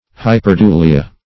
Search Result for " hyperdulia" : The Collaborative International Dictionary of English v.0.48: Hyperdulia \Hy`per*du*li"a\, n. [Pref. hyper- + dulia: cf. F. hyperdulie.]